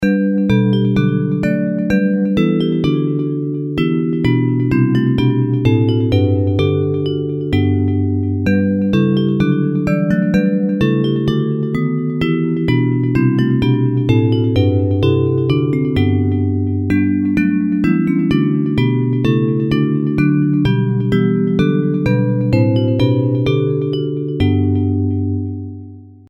Hymns of praise
Bells Version